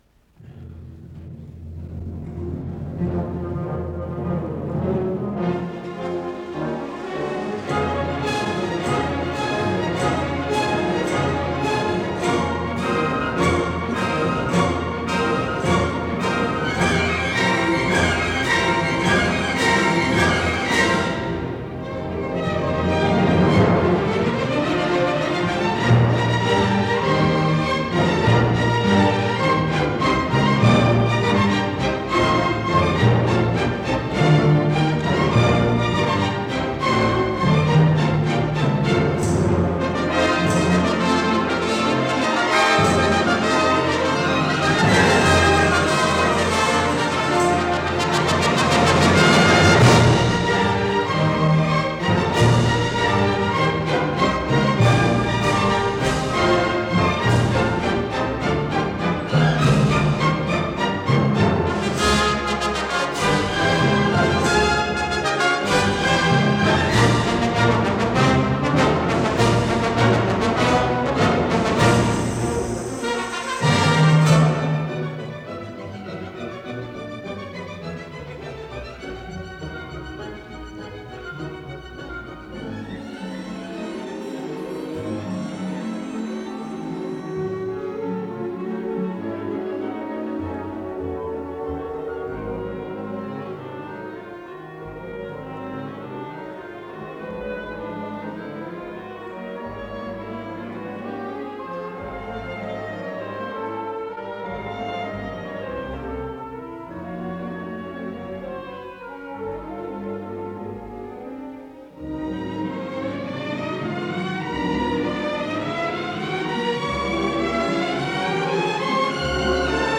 Исполнитель: Государственный симфонический оркестр СССР
Симфония №2 си бемоль минор в четырёх частях